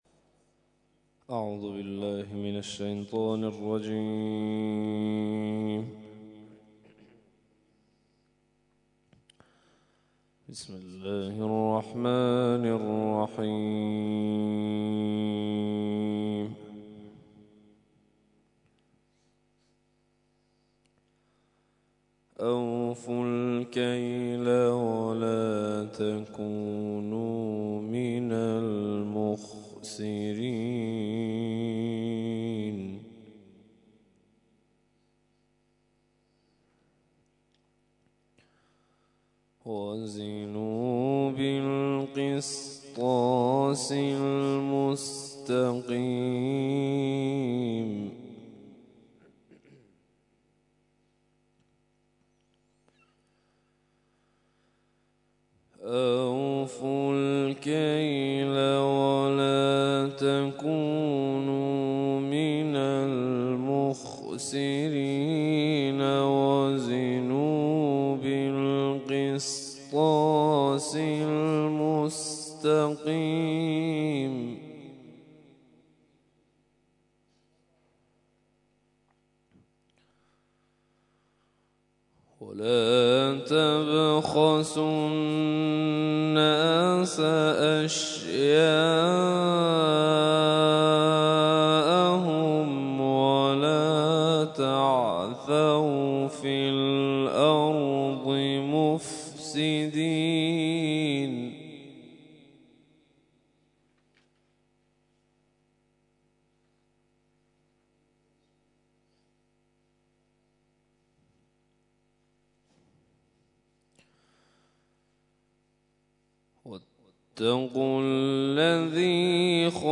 تلاوت صبح